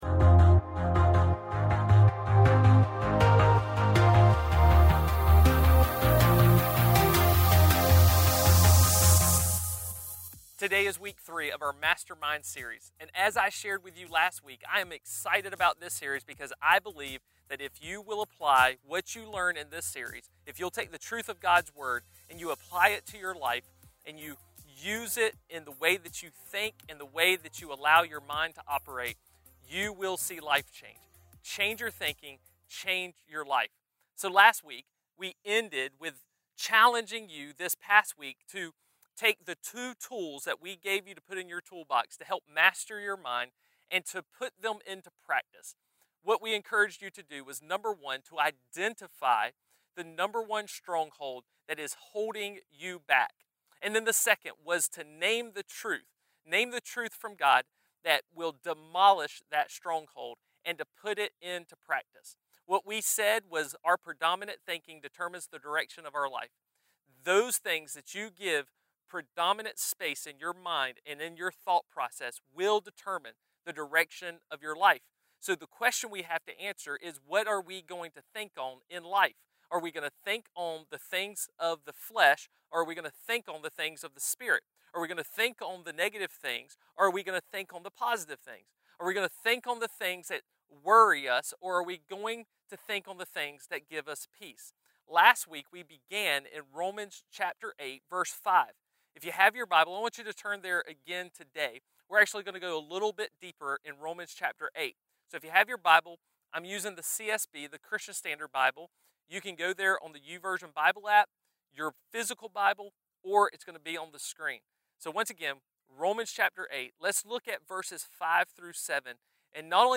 A message from the series "Unhindered."